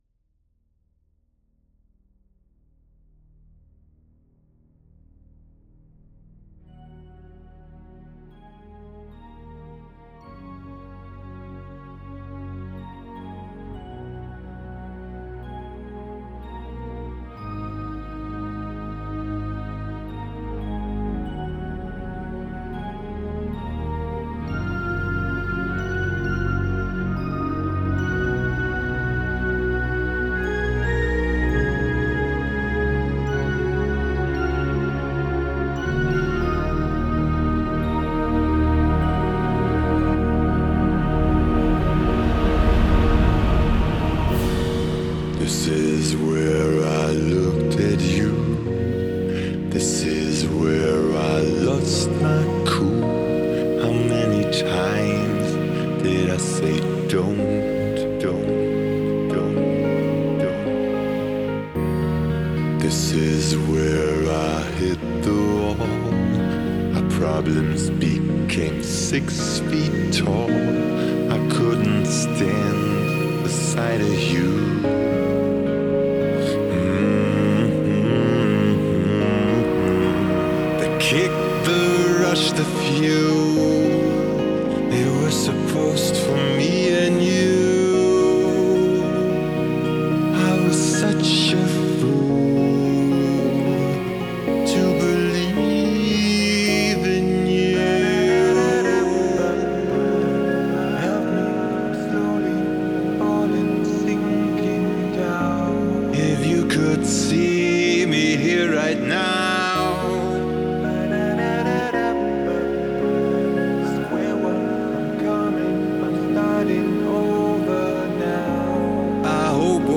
(traurige Halb-Ballade, männl. Gesang) - Eure größten Kritikpunkte
Bevor ich das Lied final release, möchte ich noch am Stereobild arbeiten, was hier eigentlich garnicht groß angefasst wurde.